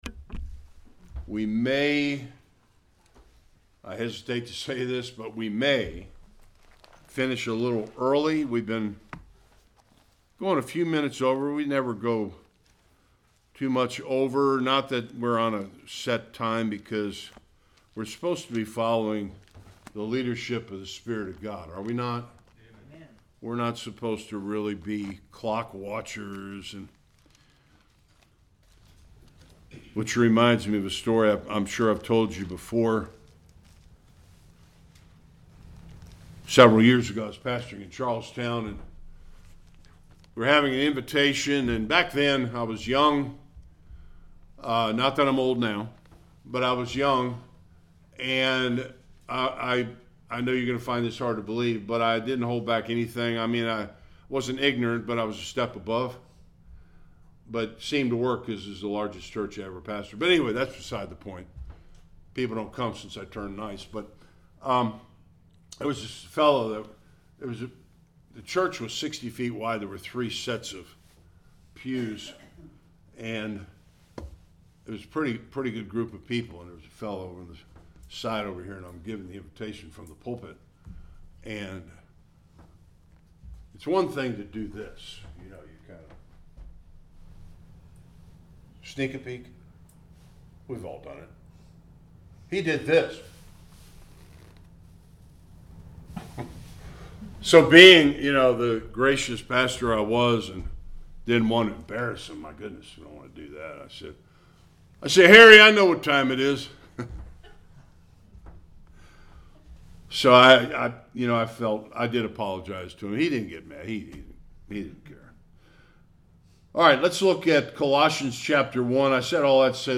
24-29 Service Type: Sunday Worship God used Paul’s suffering to advance His Kingdom.